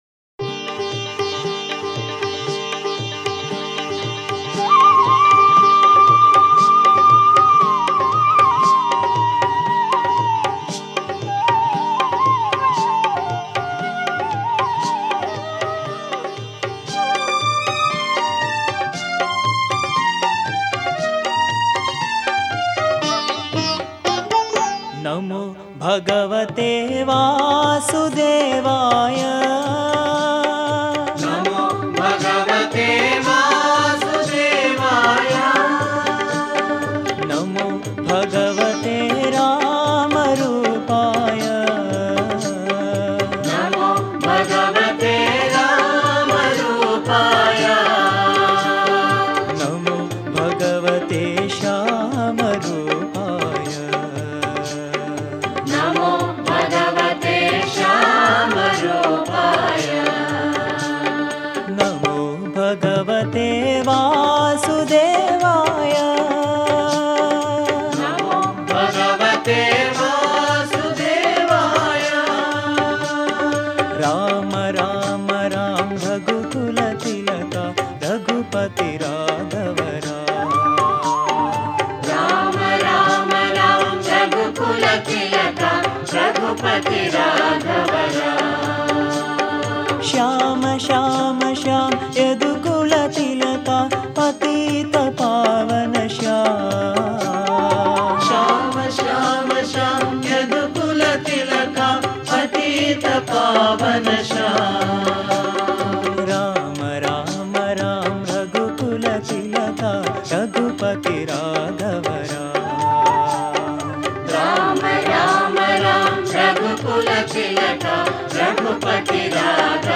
Home | Bhajan | Bhajans on various Deities | Sarva Dharma Bhajans | 26 NAMO BHAGAVATEY VASUDEVAYA